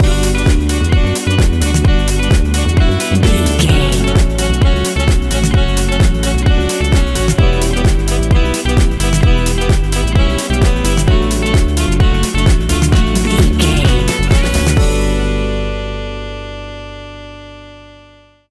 Aeolian/Minor
uplifting
energetic
bouncy
synthesiser
electric piano
bass guitar
strings
saxophone
drum machine
groovy
instrumentals